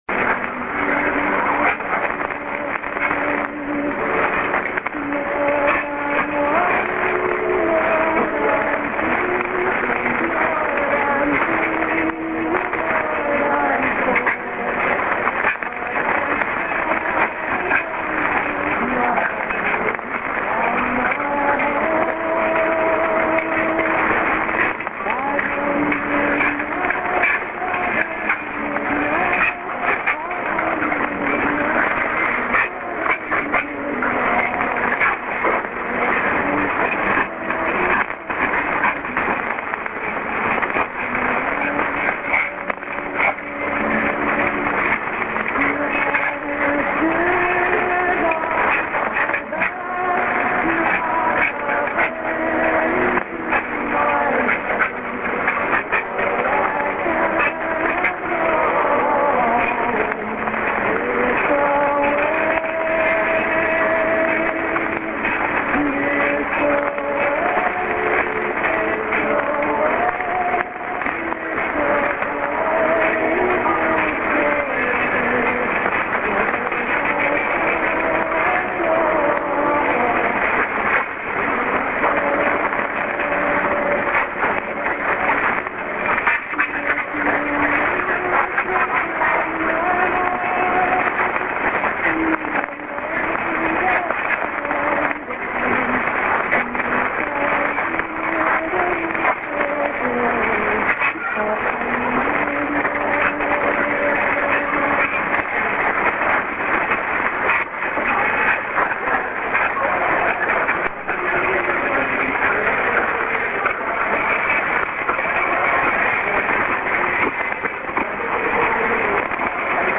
・このＨＰに載ってい音声(ＩＳとＩＤ等)は、当家(POST No. 488-xxxx)愛知県尾張旭市)で受信した物です。
DZSD　St. music-music->03'00":ID(man)->